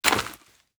Dirt footsteps 16.wav